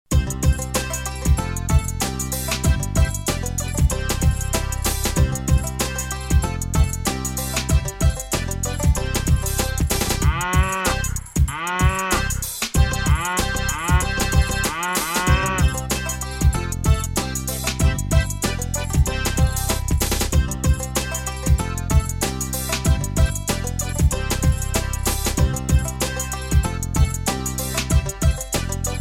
دانلود آهنگ حیوانات 43 از افکت صوتی انسان و موجودات زنده
دانلود صدای حیوانات 43 از ساعد نیوز با لینک مستقیم و کیفیت بالا
جلوه های صوتی
برچسب: دانلود آهنگ های افکت صوتی انسان و موجودات زنده دانلود آلبوم مجموعه صدای حیوانات مختلف با سبکی خنده دار از افکت صوتی انسان و موجودات زنده